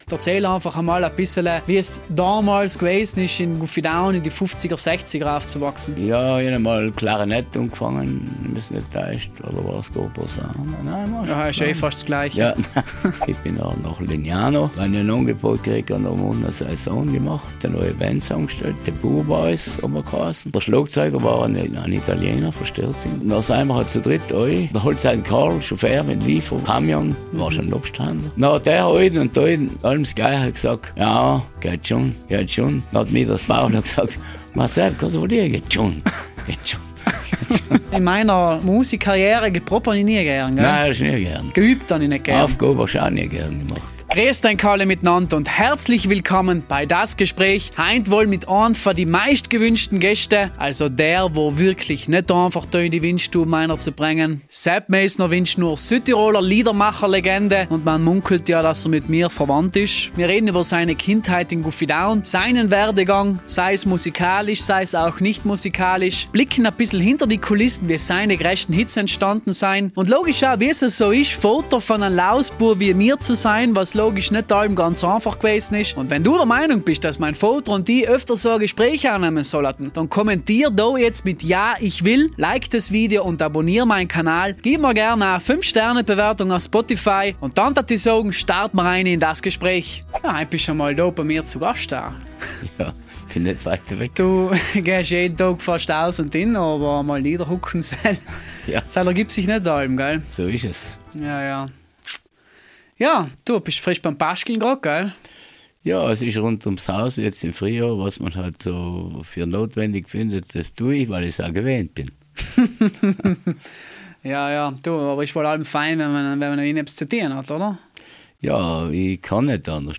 Freut euch auf eine sehr persönliche, ehrliche und gleichzeitig unterhaltsame Folge – ein Gespräch zwischen Vater und Sohn.